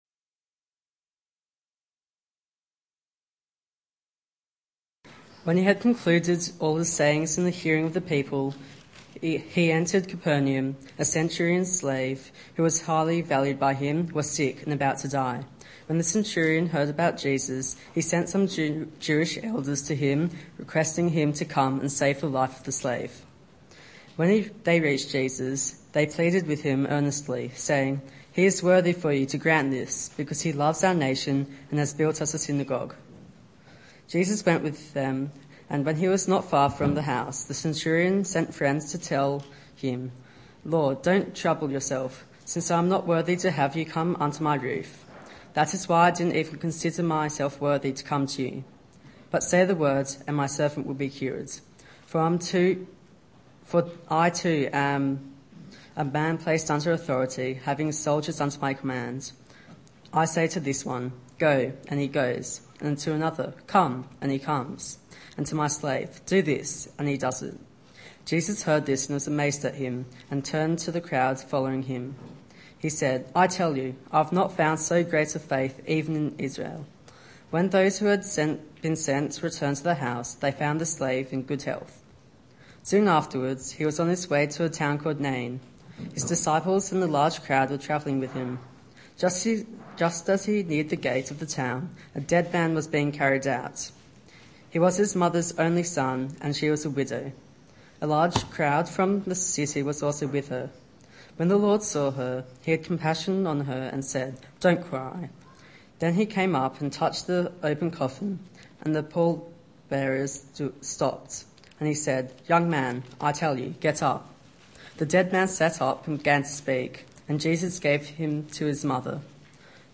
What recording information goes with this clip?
Youth Church